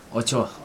[ot͡ɕo] adverb here